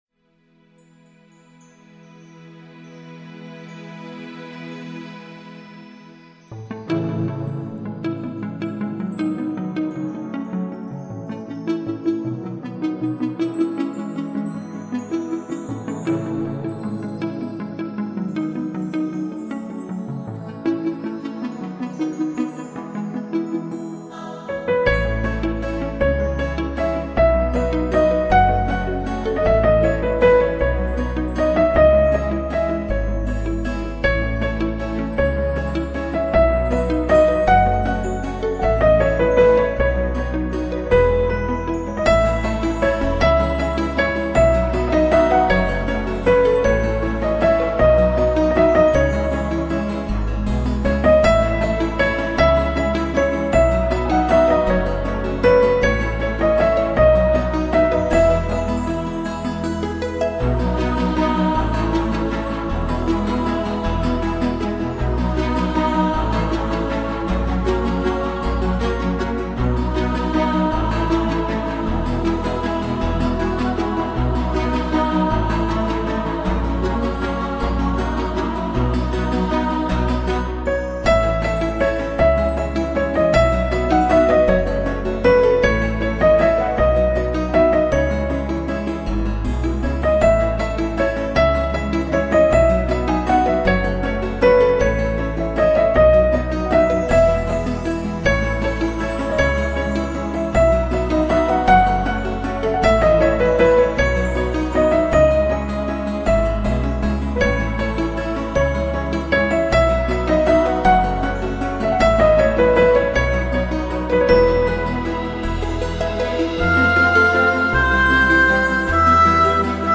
也拥有最自然脱俗的音乐风格。
以清爽的配乐架构出零压力，零负担的乐曲，
使音域更宽广，音场效果更具空灵感，
完全让你融入到了大自然中去……